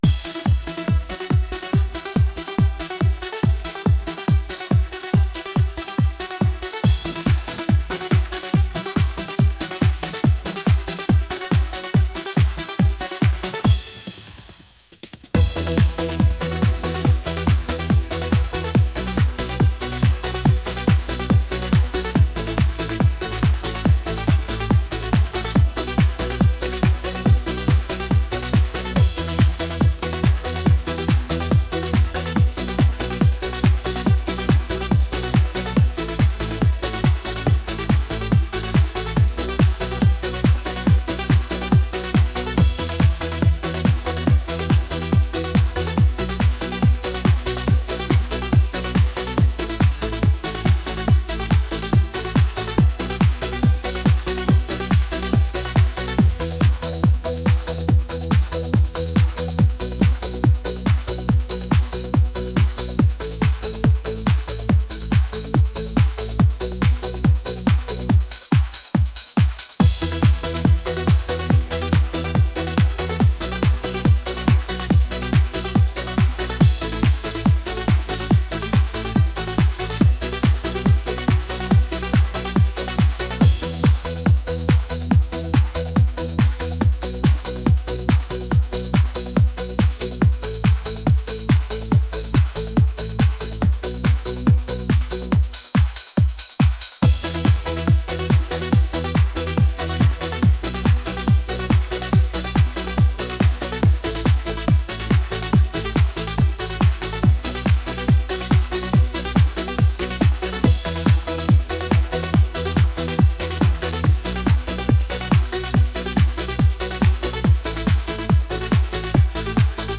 upbeat | energetic